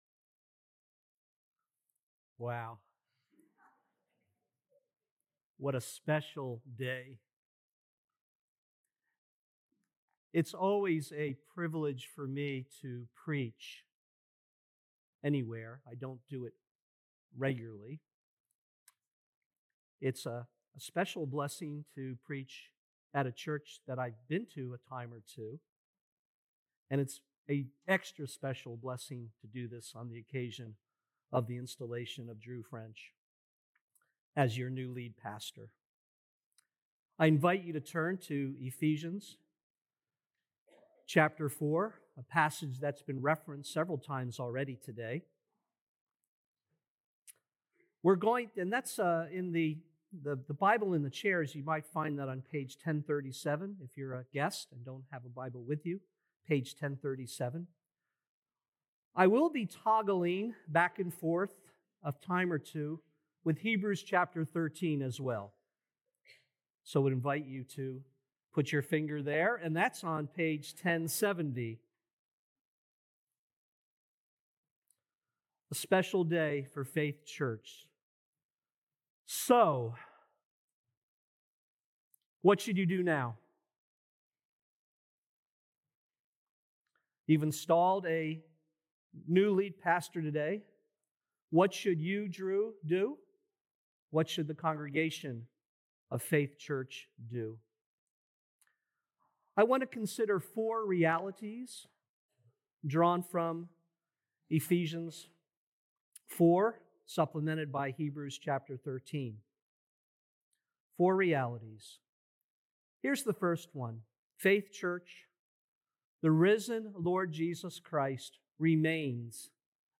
Apr 19th Sermon | Ephesians 4:7-16